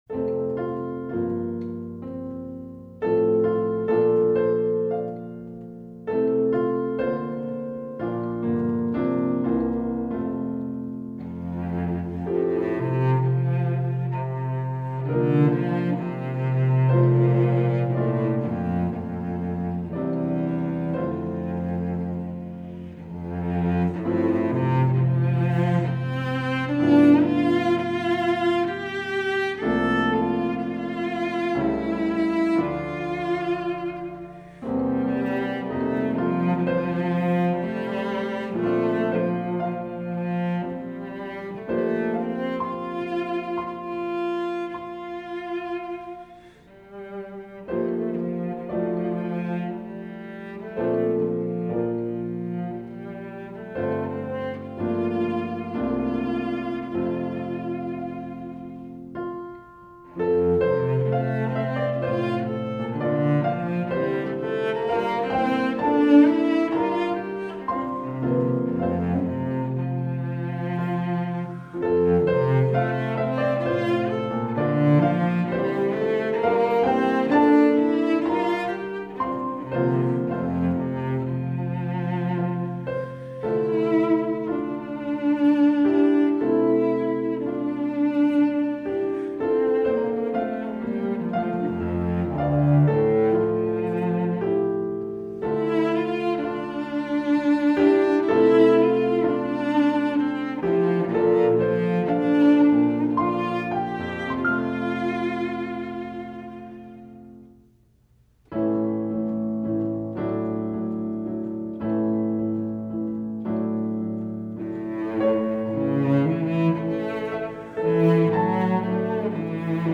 Voicing: Cello and Piano